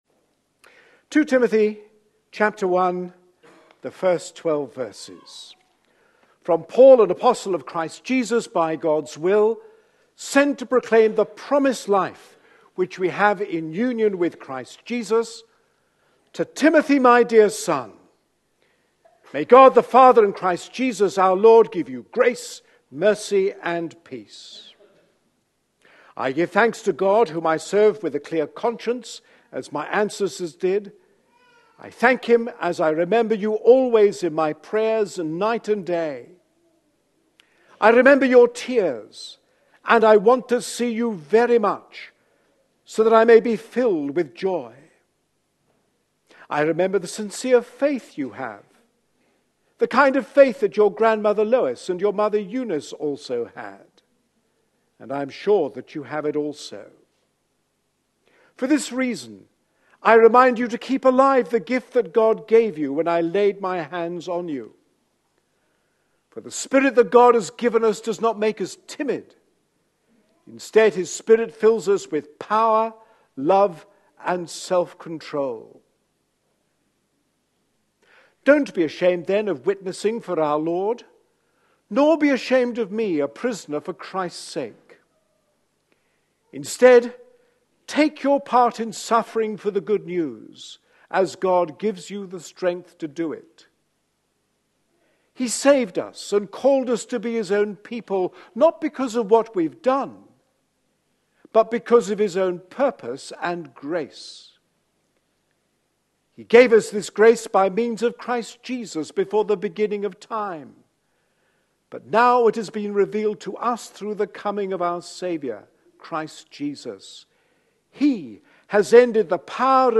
A sermon preached on 29th December, 2013.